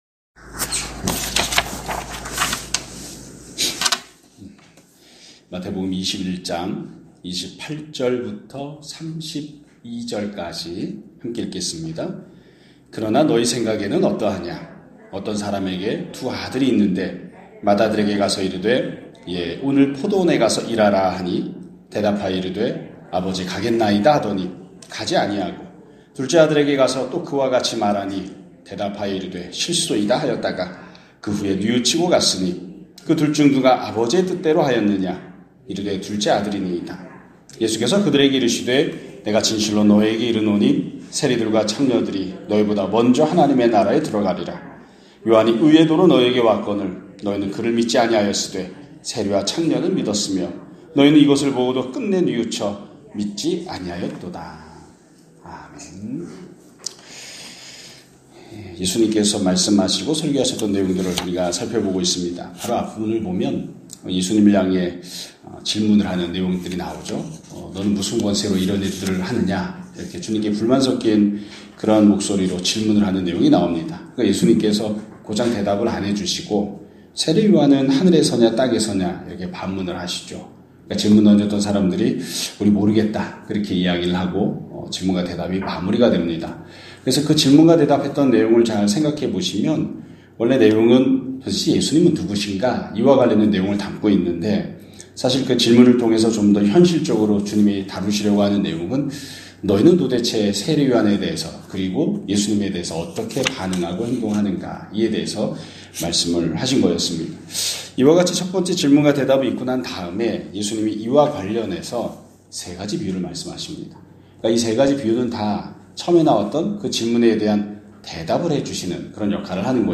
2026년 2월 2일 (월요일) <아침예배> 설교입니다.